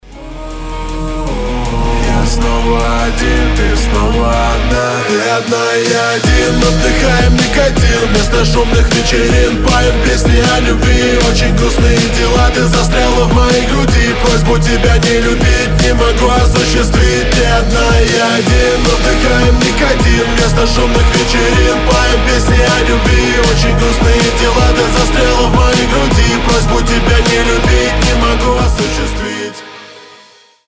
• Качество: 320, Stereo
громкие
Драйвовые
Рэп-рок